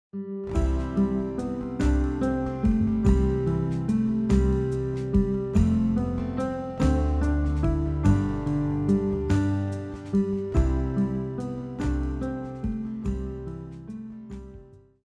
• Guitar arrangement